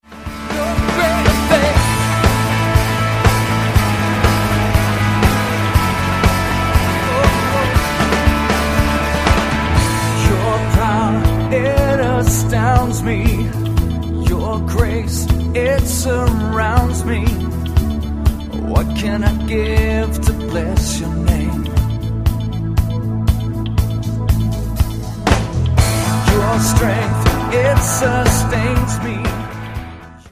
live worship
• Sachgebiet: Praise & Worship